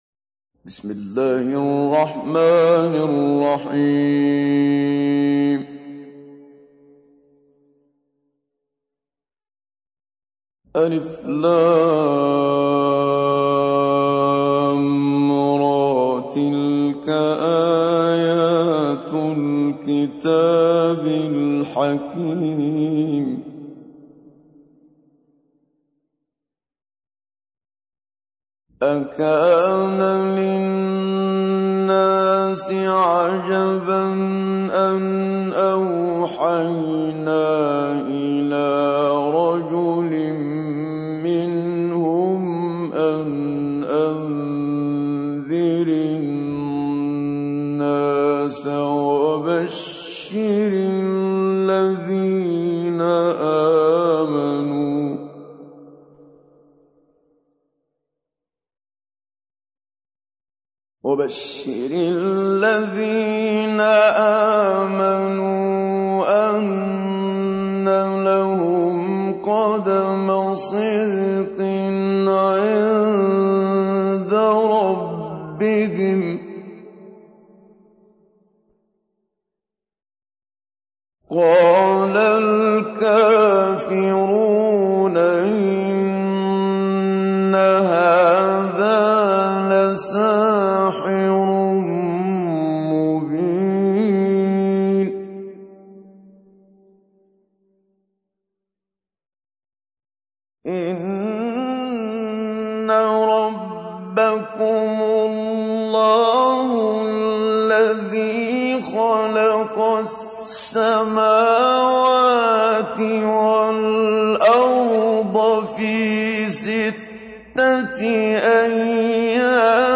تحميل : 10. سورة يونس / القارئ محمد صديق المنشاوي / القرآن الكريم / موقع يا حسين